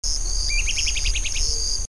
BRAN-COLOURED FLYCATCHER Myiophobus fasciatus
Myiophobus fasciatus song recorded PROCOSARA, PN San Rafael
Myiophobusfasciatus (2).wav